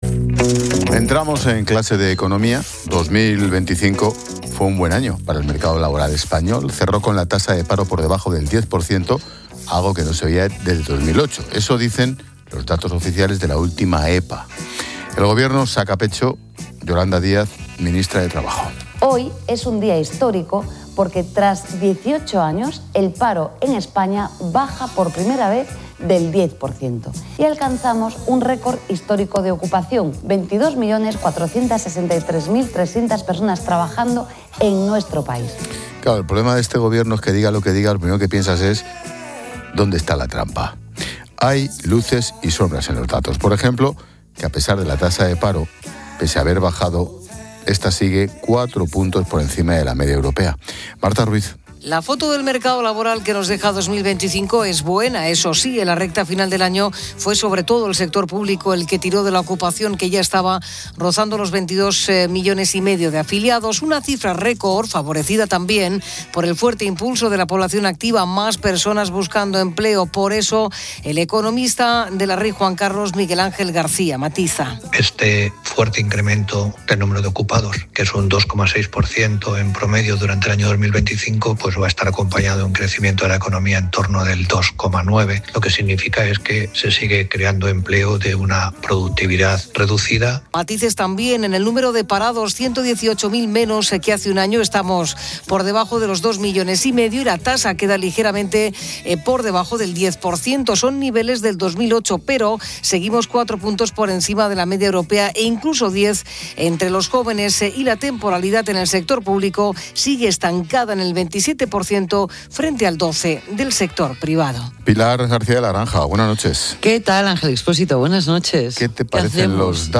Expósito aprende en Clases de Económica con la experta económica y directora de Mediodía COPE, Pilar García de la Granja, sobre los datos del mercado laboral de 2025